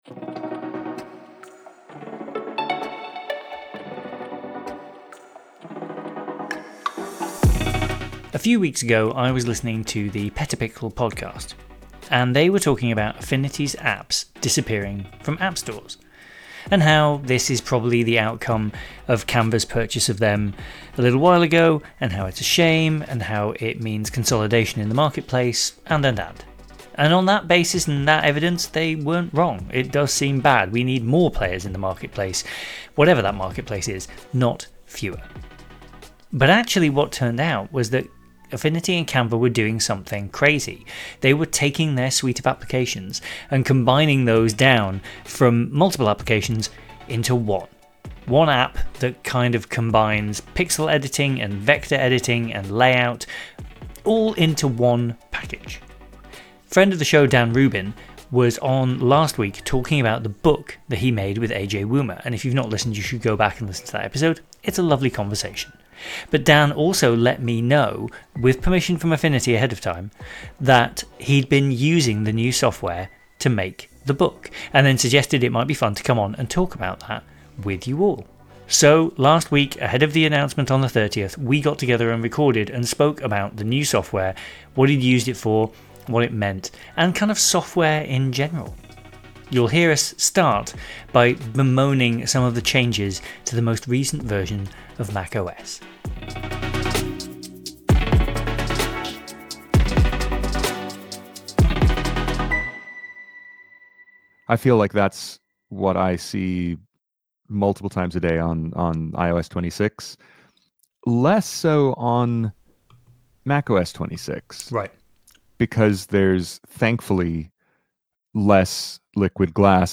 Prime Lenses is a series of interviews with pho tographers talking about their photography by way of three lenses that mean a lot to them.